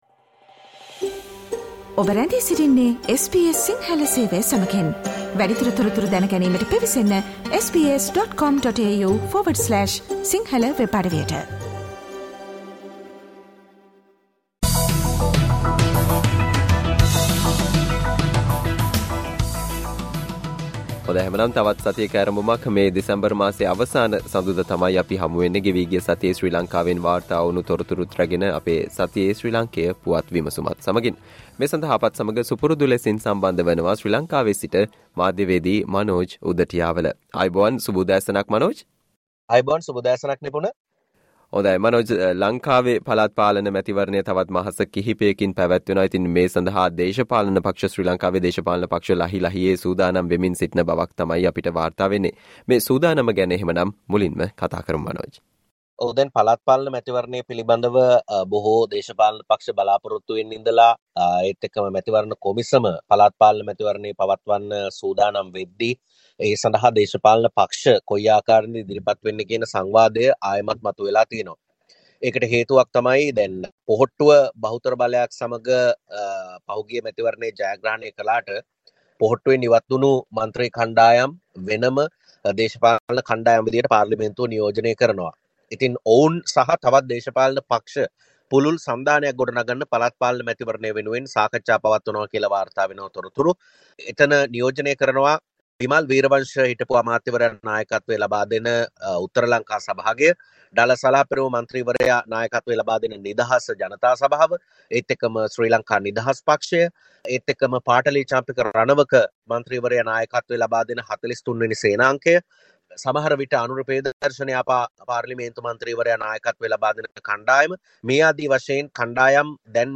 Taxes to rise in 2023 while SL Police Acts changes: Sri Lankan News Wrap